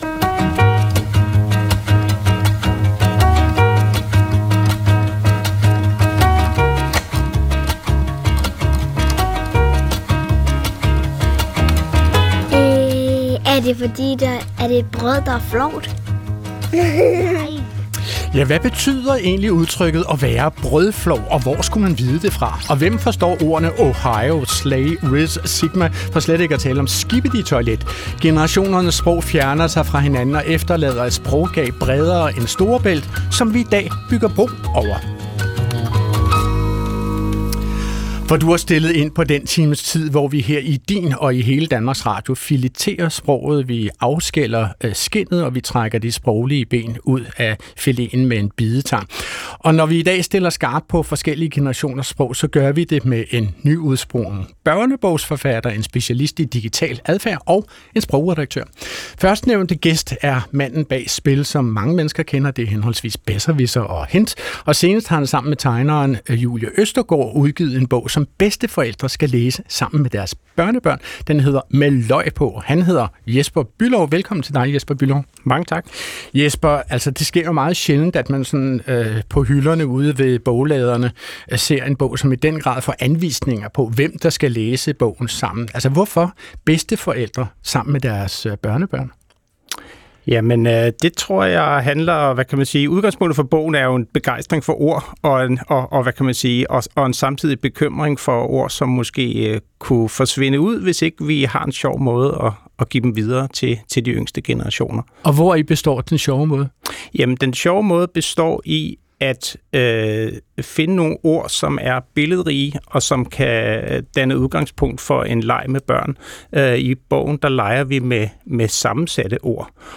Fredericia Missionhus, nov 2024